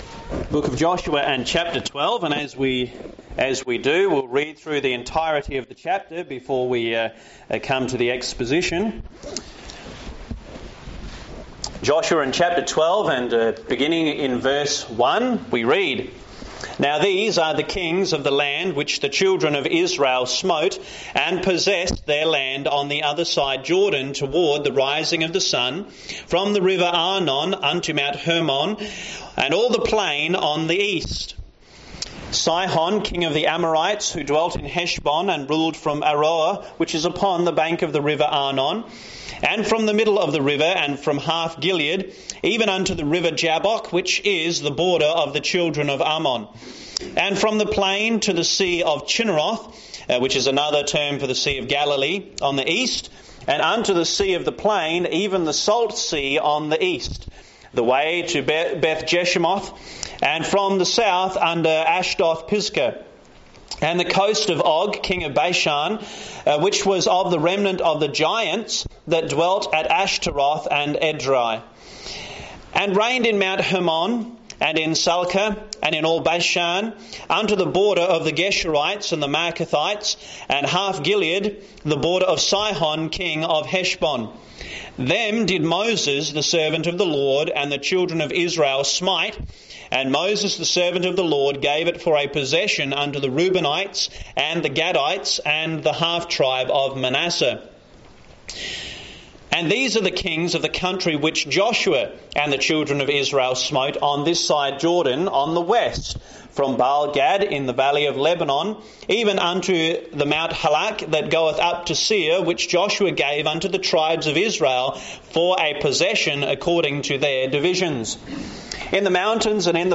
This message from Joshua 12 teaches believers that God keeps His promises, gives victory by faith, and still leads His people today.